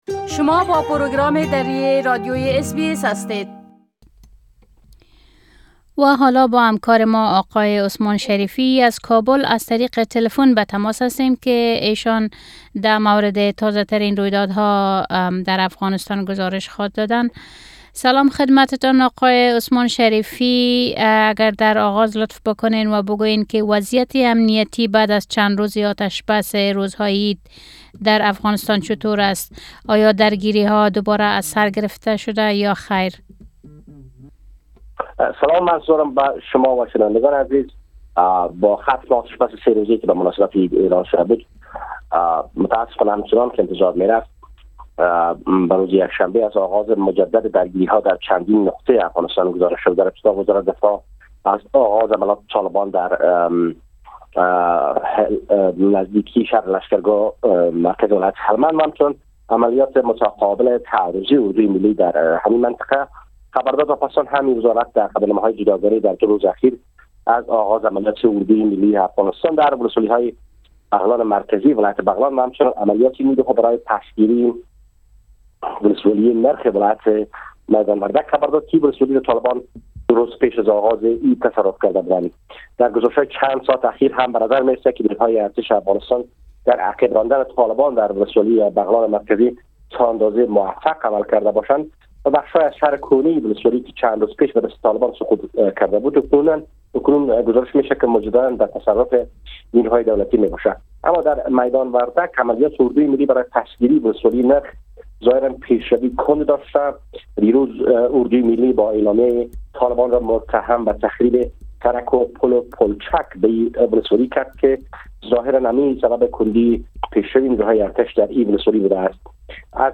خبرنگار ما در کابل: پس از آتش بس سه روزه عید، درگیری ها دوباره در افغانستان آغاز شده است
پس از آتش بس سه روزه در عید، درگیری های نظامی بین طالبان و نیرو های افغان دوباره آغاز شده است. گزارش كامل خبرنگار ما در كابل، به شمول اوضاع امنيتى و تحولات مهم ديگر در افغانستان را در اينجا شنيده ميتوانيد.